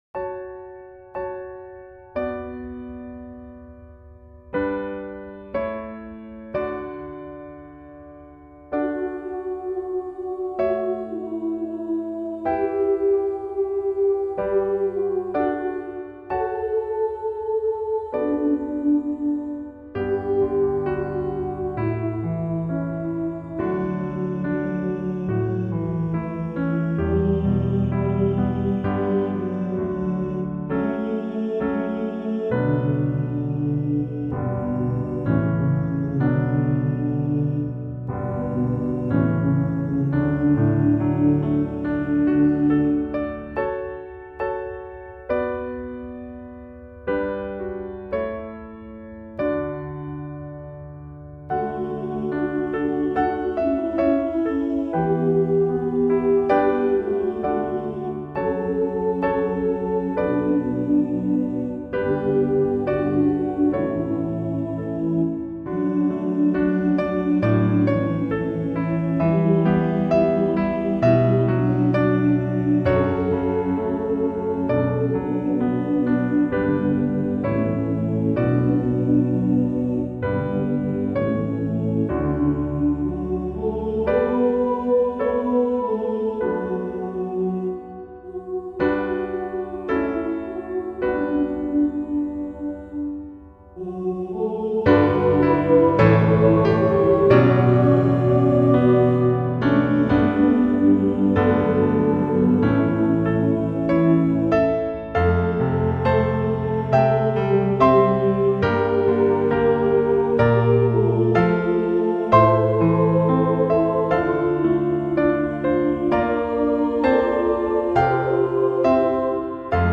SATB (Key of D)